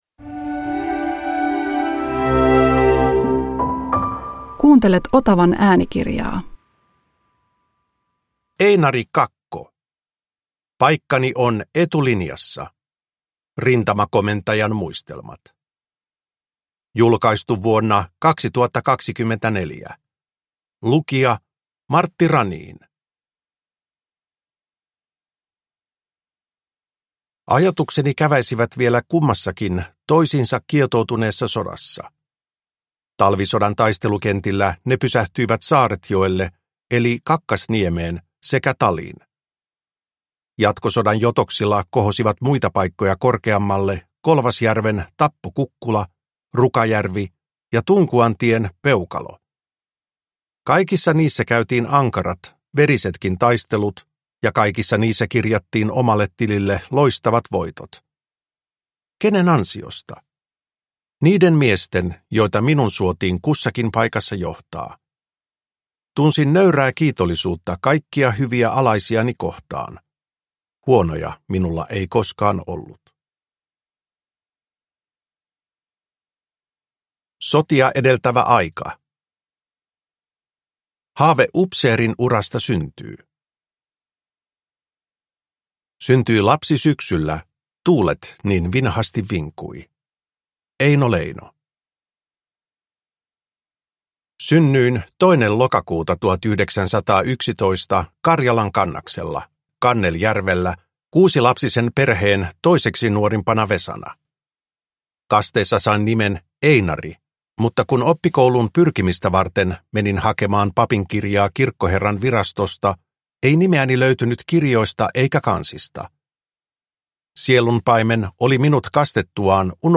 Paikkani on etulinjassa – Ljudbok